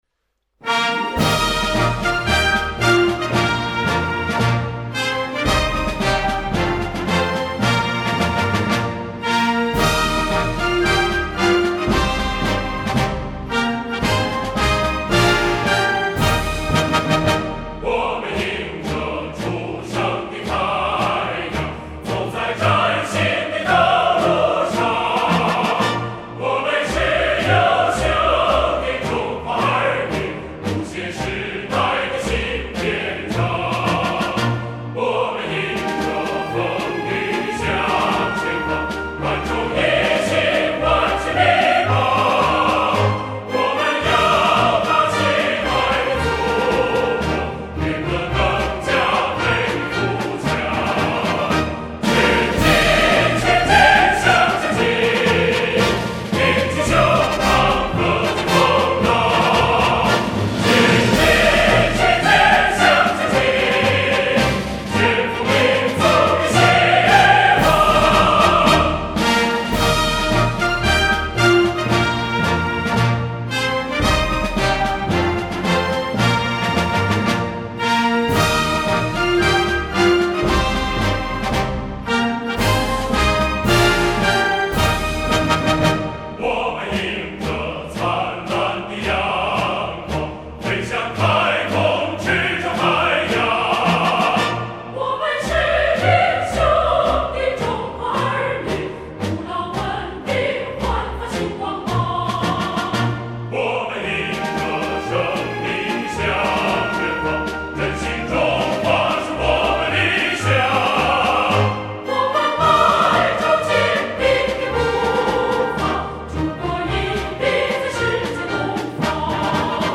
混声合唱